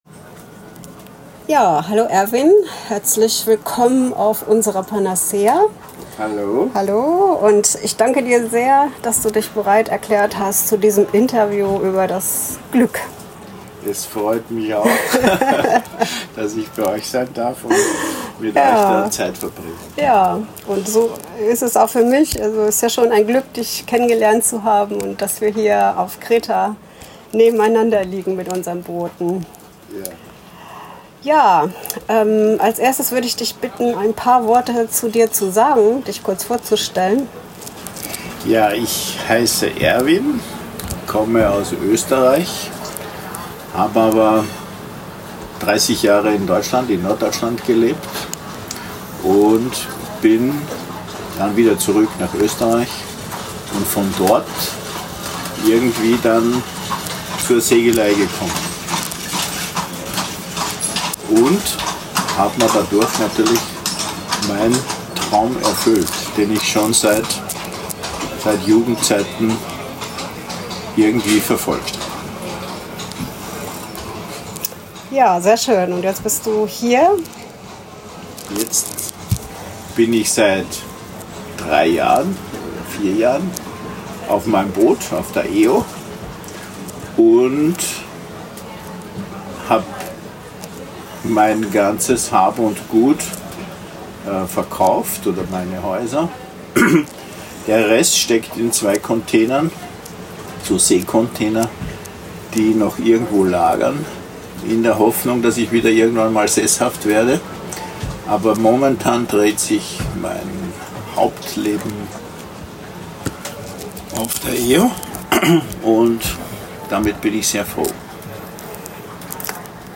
Zudem spricht er über das Glück, trotz gesundheitlicher Herausforderungen seinen Traum weiterleben zu können. Ein inspirierendes Gespräch über Mut, Veränderung und die Suche nach dem persönlichen Glücksort.